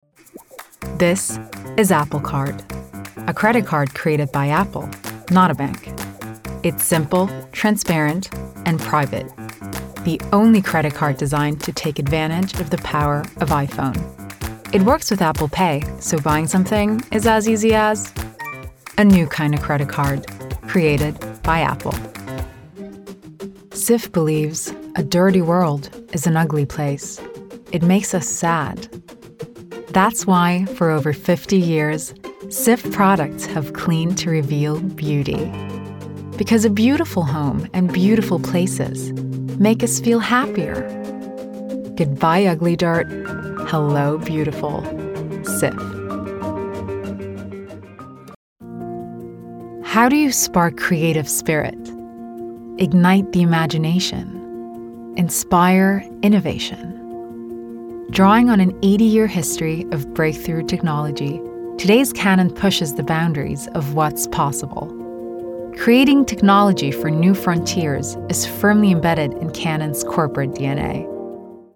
German, Female, Home Studio, 20s-30s